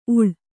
♪ uḷ